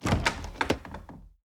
Chest Open 2.ogg